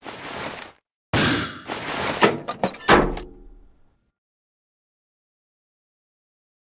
box_pack.ogg